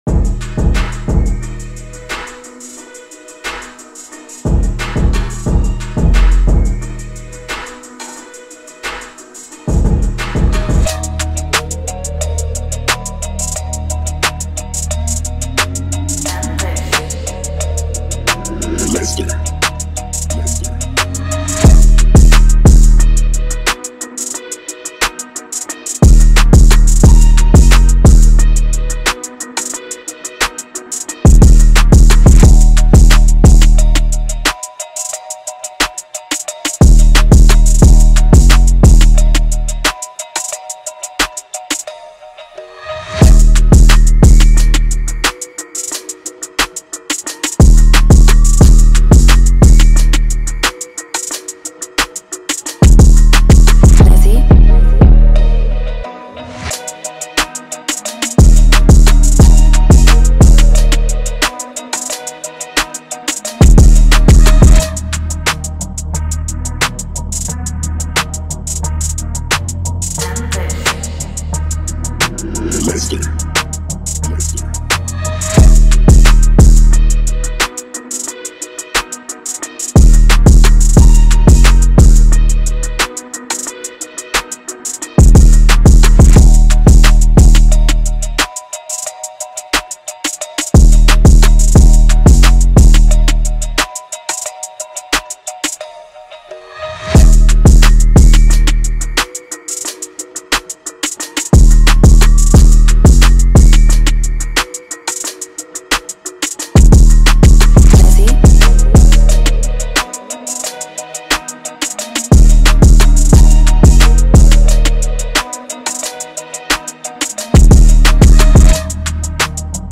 ژانر : ترپ مود : مامبل |گنگ | دیس تمپو : 89 زمان